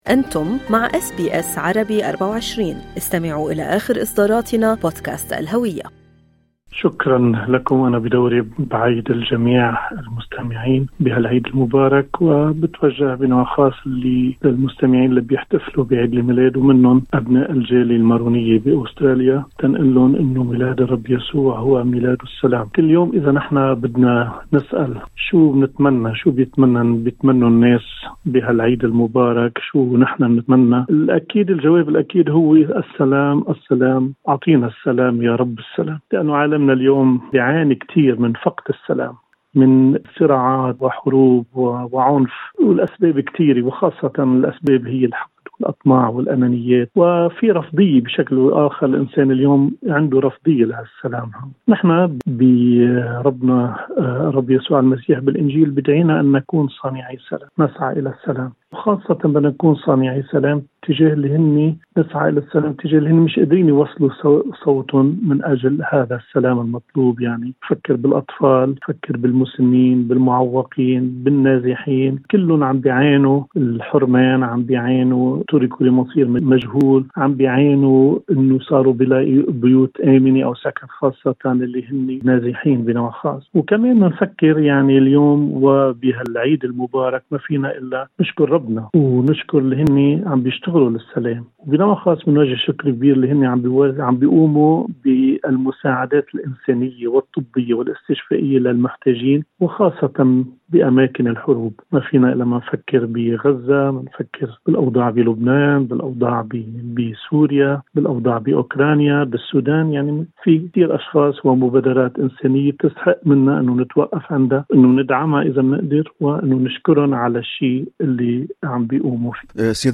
وفي حديثه مع إذاعة أس بي أس عربي، توجه سيادة المطران أنطوان شربل طربيه راعي الأبرشية المارونية في أستراليا ونيوزلندا وأوقيانا، بالتهنئة لجميع أبناء الجالية في أستراليا بالعيد المجيد.